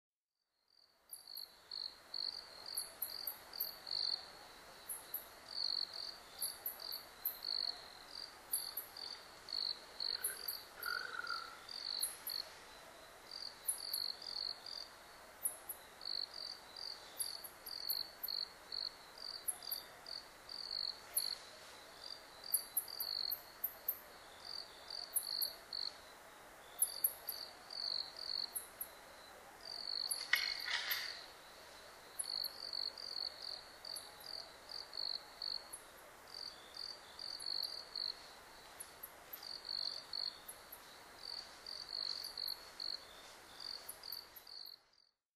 コウモリ sp.　a kind of a Bat
Mic.: Sound Professionals SP-TFB-2  Binaural Souce
他の自然音：　 ツヅレサセコオロギ・四つ足動物の足音